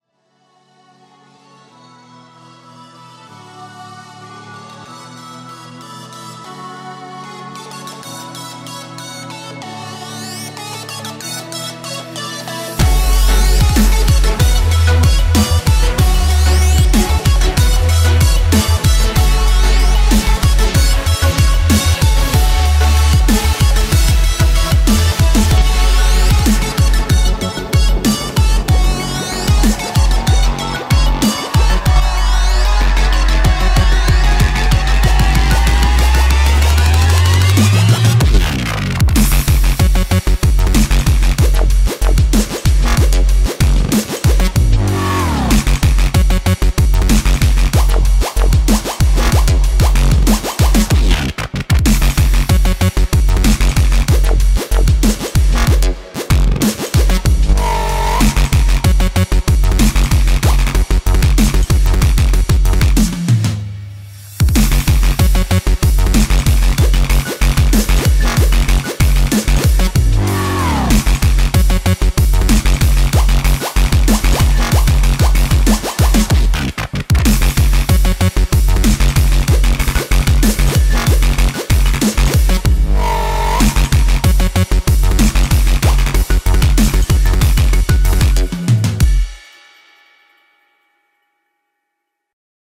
BPM188--1